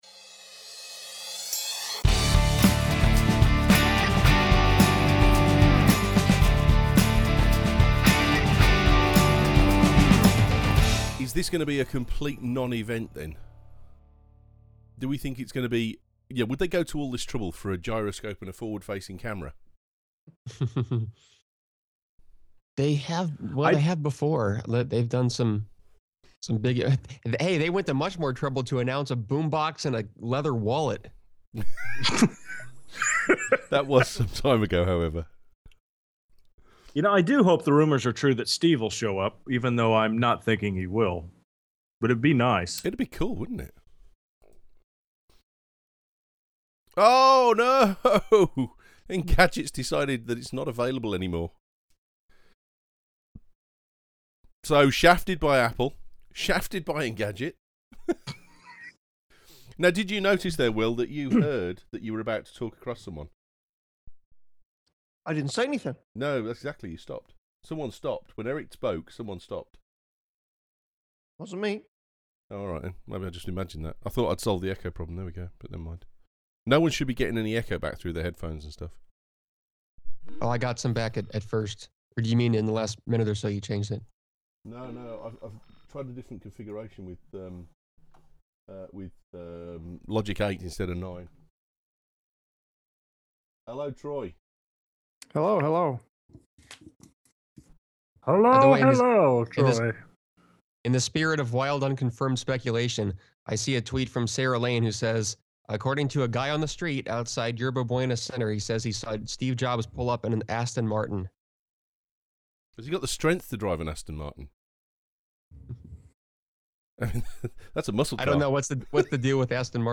OK its uncut audio from the Launch last night, but there are some choice comments in there